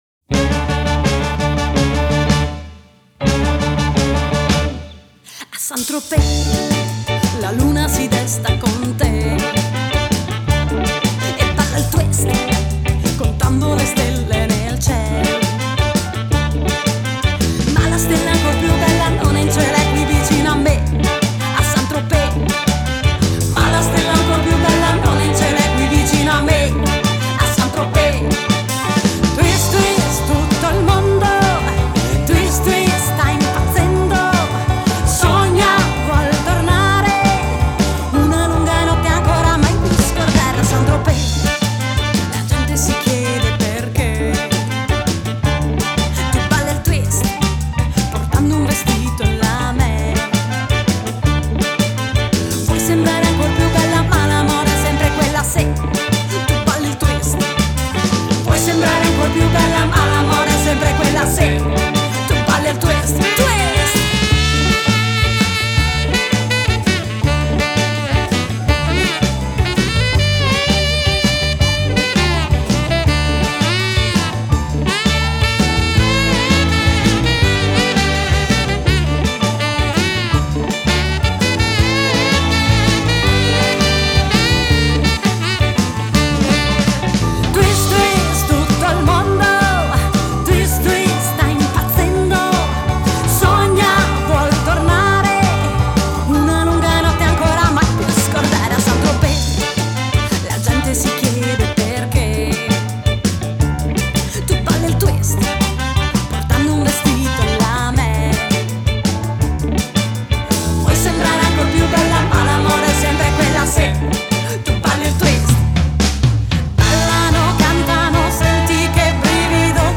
Revival ‘50 ‘60 Italia America